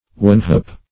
Search Result for " wanhope" : The Collaborative International Dictionary of English v.0.48: Wanhope \Wan"hope`\, n. [AS. wan, won, deficient, wanting + hopa hope: cf. D. wanhoop.